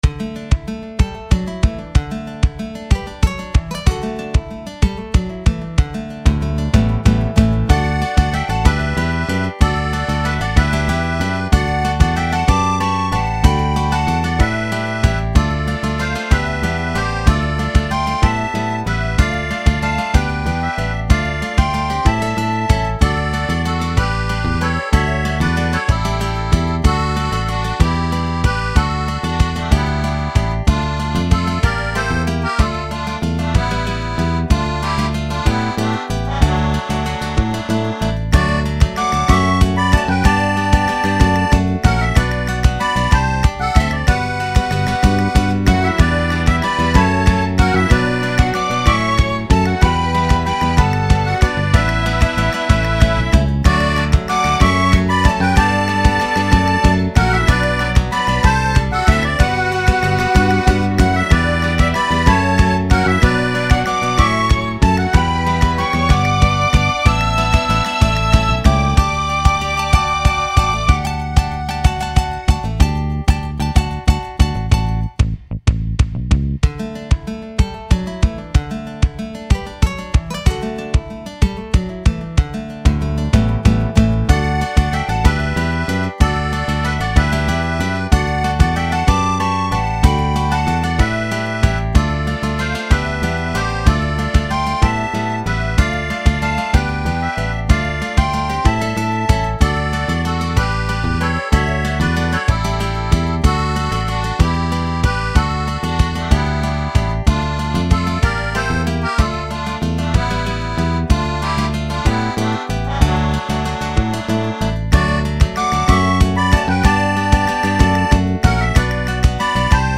カントリーロング明るい穏やか